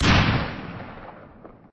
normal zombie die 7.mp3